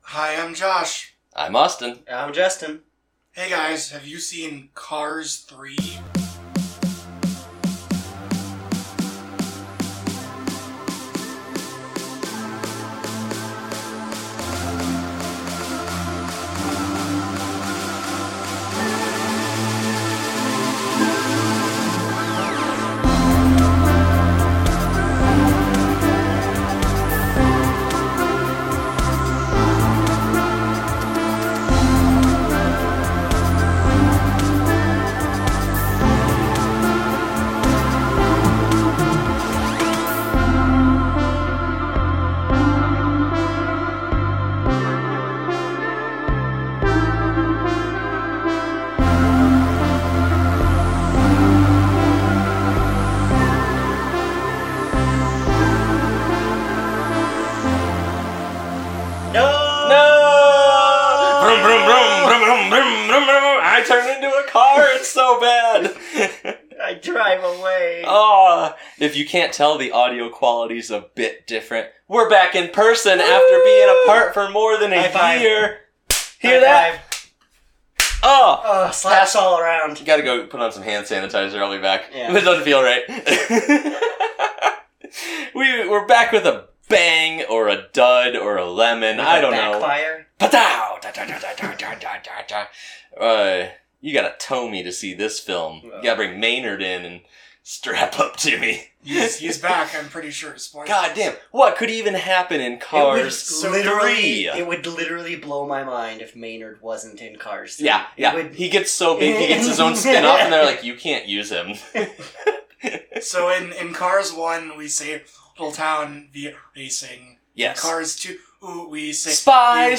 Listen to this episode 0:00 / 0:00 Learn More Summary Cars 3 (2017) was the first movie we taped in person after more than a year of remote watches and recording.